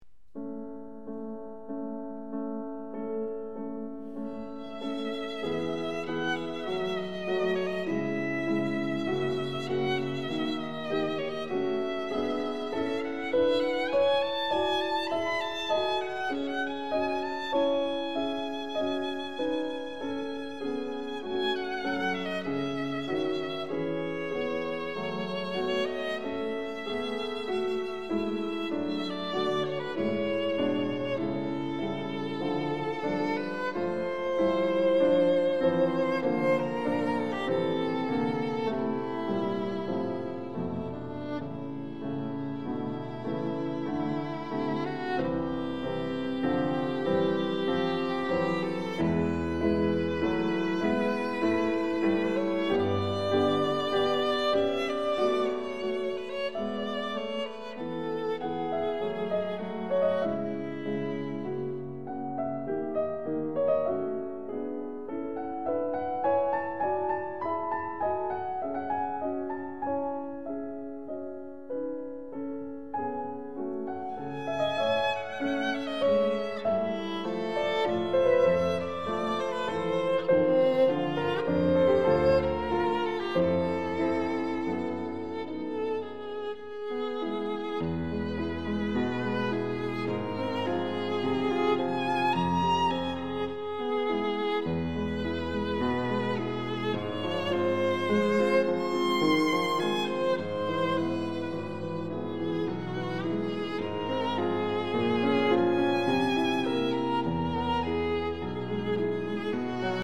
★ 著名工程師採單點錄音，高傳真效果完美呈現！
★ 令人顫抖著迷的小提琴美音之最，發燒必備珍品！
Violin Sonata No.2 in E minor